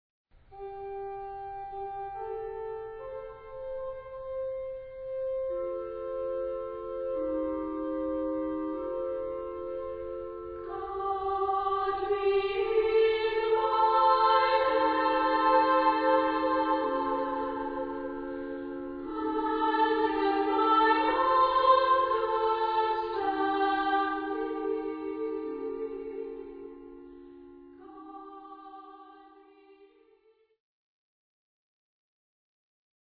Anthem for SA and organ